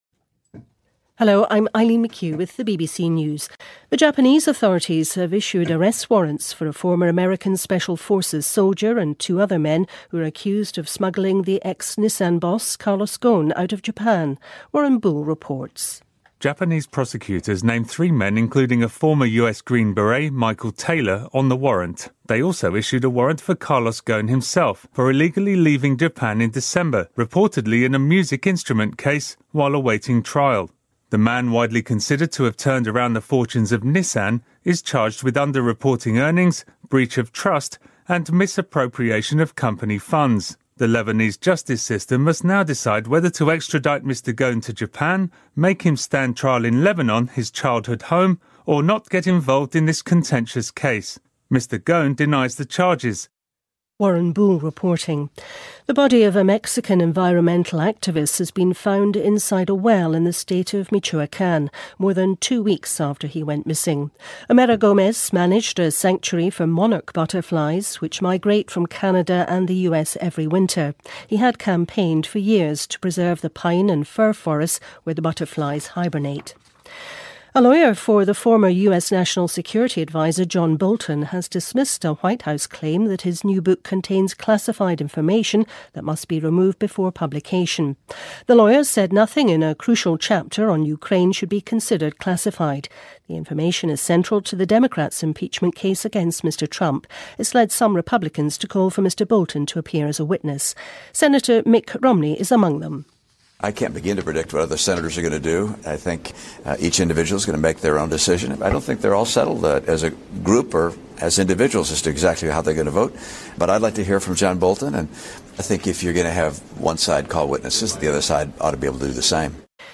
News
英音听力讲解:加拿大女足队长打破国际比赛进球数纪录